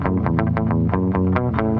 8 Harsh Realm Bass Riff B Long.wav